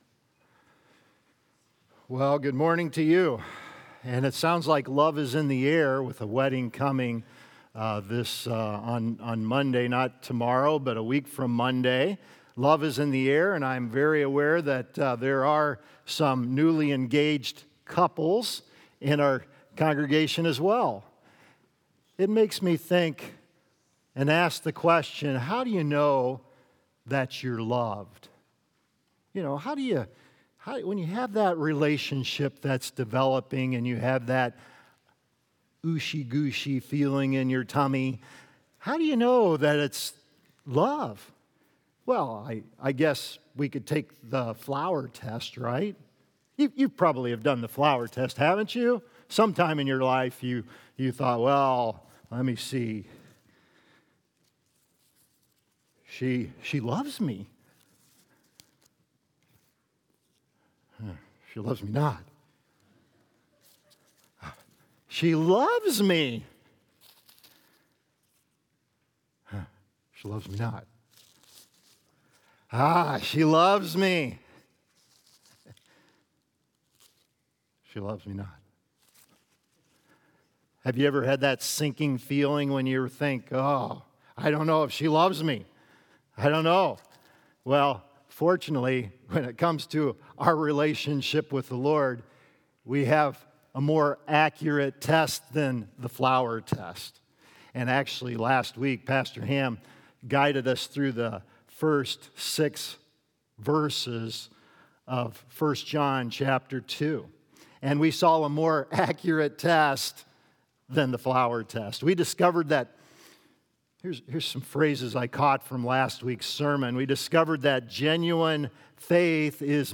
True Light, True Love | Baptist Church in Jamestown, Ohio, dedicated to a spirit of unity, prayer, and spiritual growth